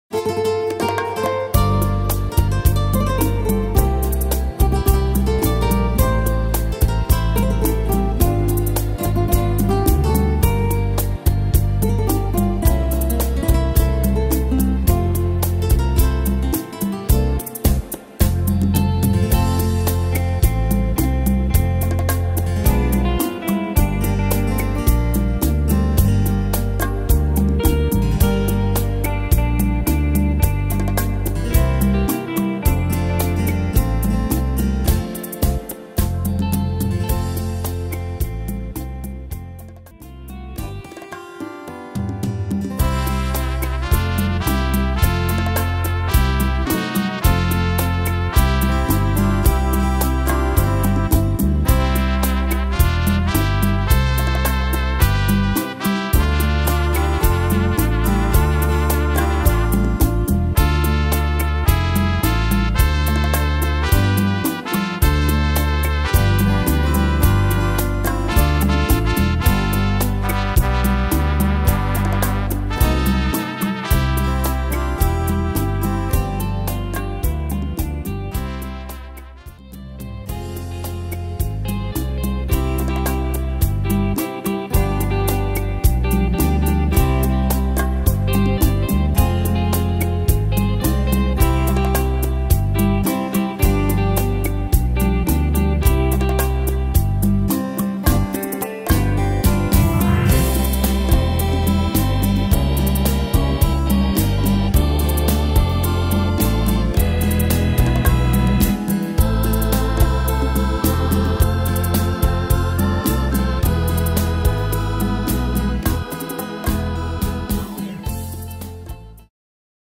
Tempo: 108 / Tonart: F-Dur